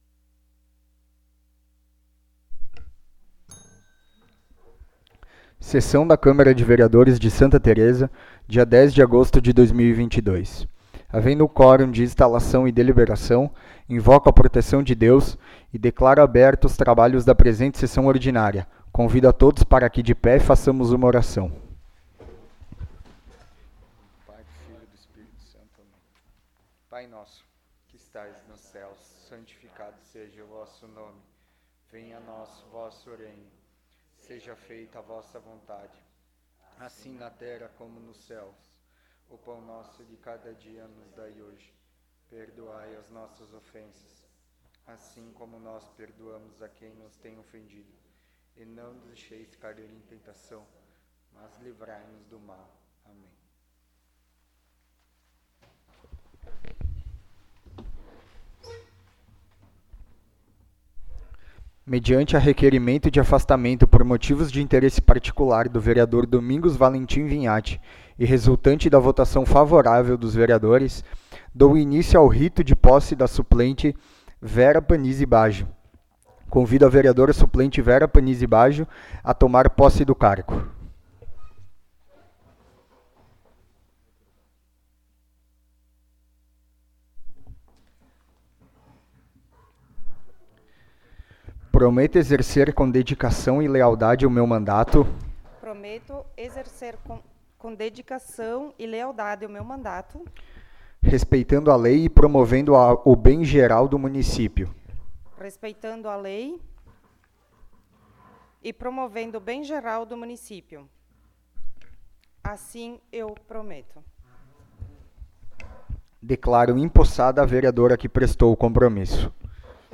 13ª Sessão Ordinária de 2022
Áudio da Sessão